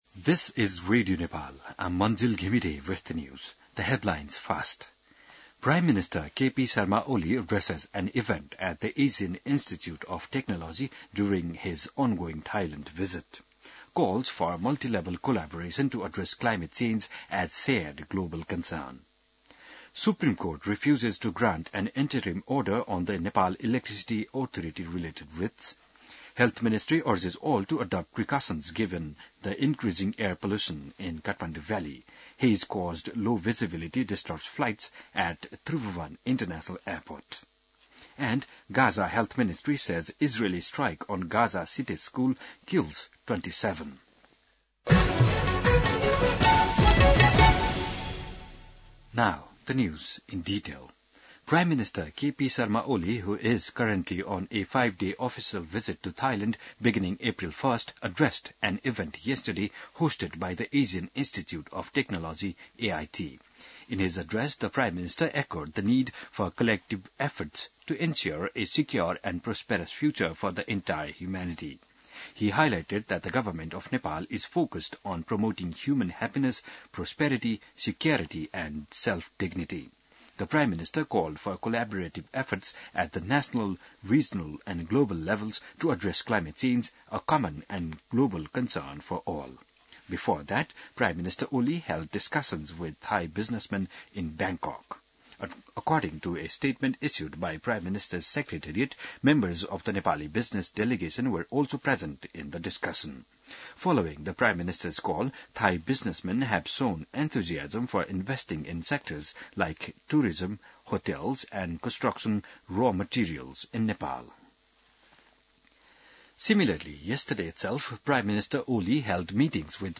An online outlet of Nepal's national radio broadcaster
बिहान ८ बजेको अङ्ग्रेजी समाचार : २२ चैत , २०८१